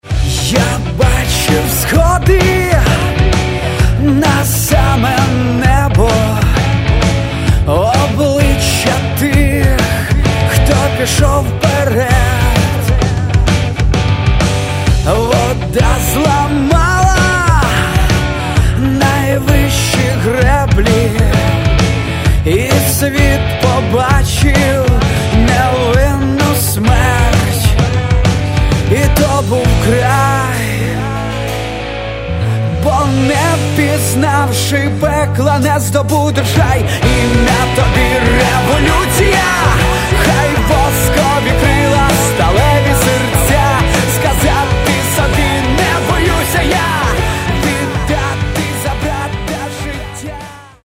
Catalogue -> Rock & Alternative -> Simply Rock